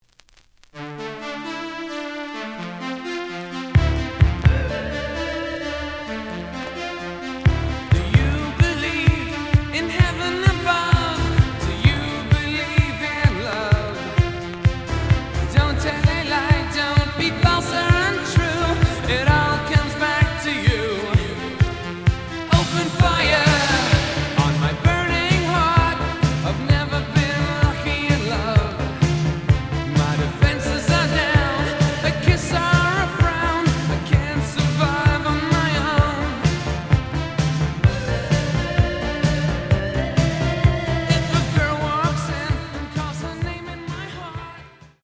試聴は実際のレコードから録音しています。
The audio sample is recorded from the actual item.
●Genre: Rock / Pop